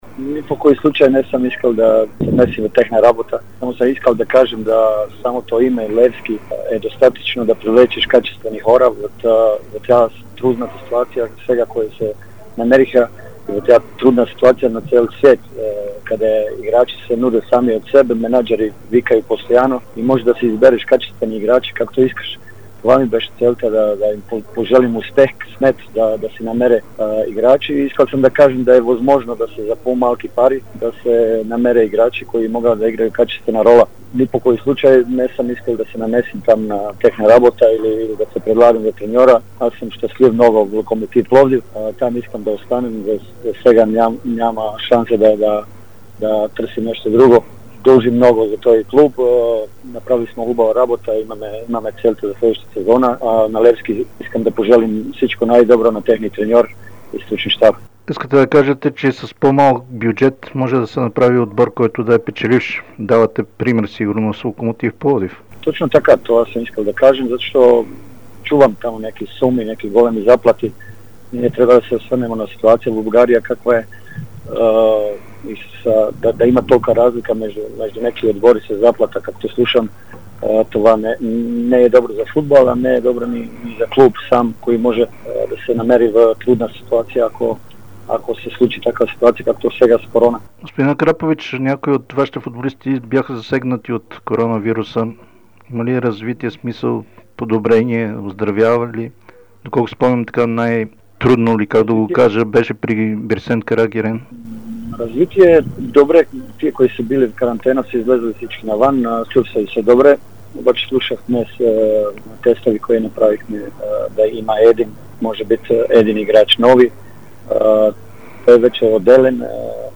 Неприятната новина разкри старши треньорът Бруно Акрапович в интервю за dsport.